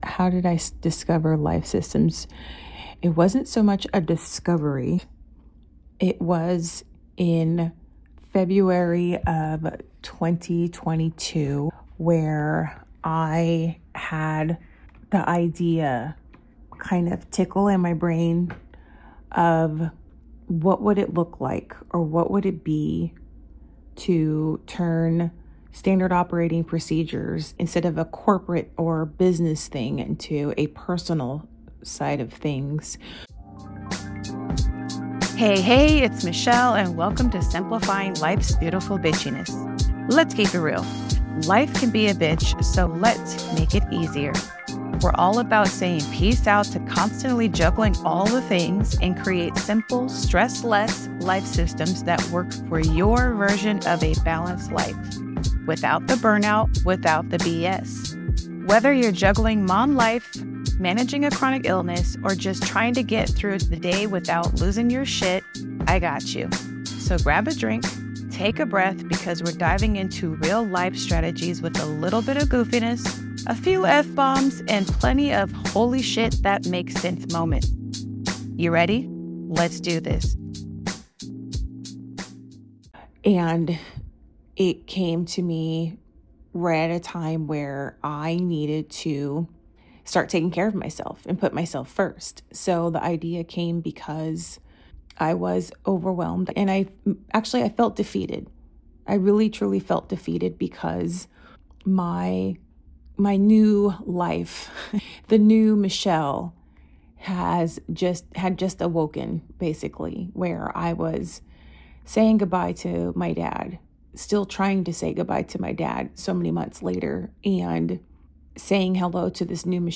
The vibe check: real talk, a few F-bombs, and plenty of “omg same” moments.